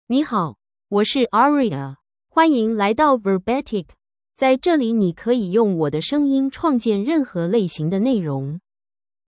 Aria — Female Chinese AI voice
Aria is a female AI voice for Chinese (Mandarin, Traditional).
Voice sample
Listen to Aria's female Chinese voice.
Female